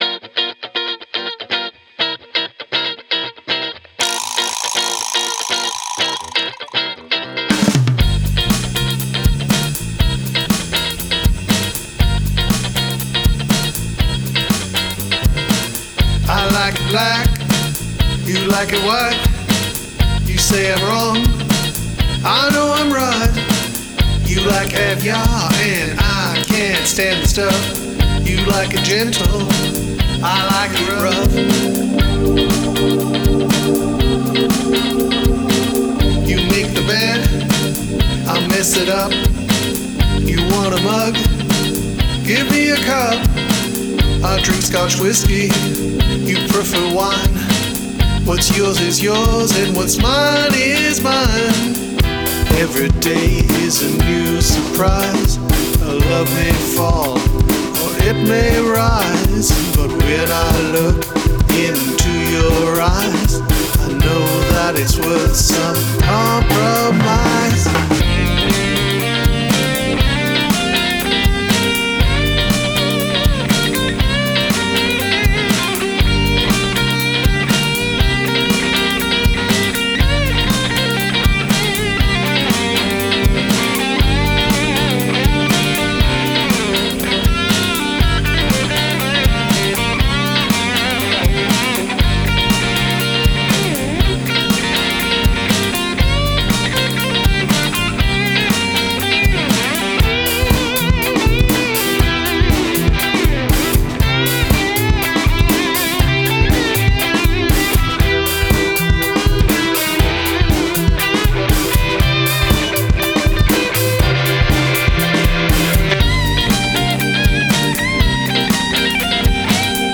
compromise-demo1.m4a